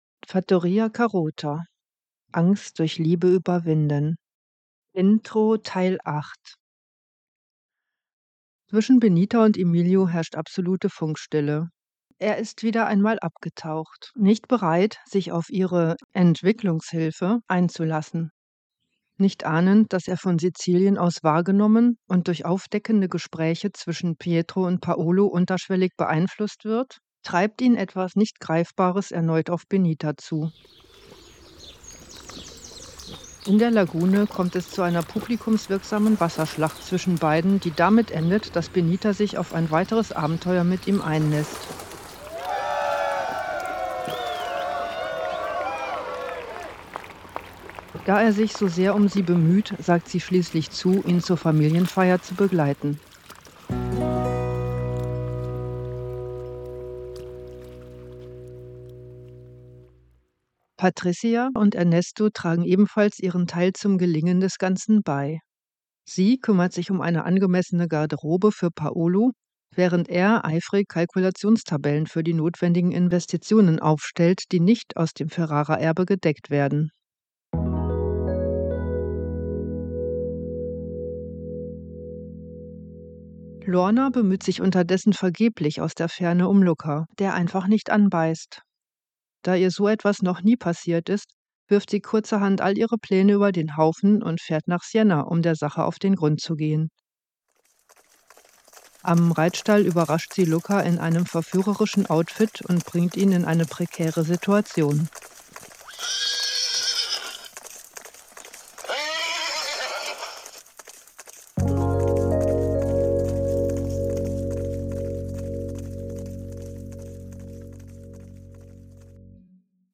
Konflikte lösen mit Humor mit Wandel-Hörspielen (auch zum Mitmachen), ausgefallenen Gedichten, experimentellen Rollenspielen, Konfliktlösungs-Sketchen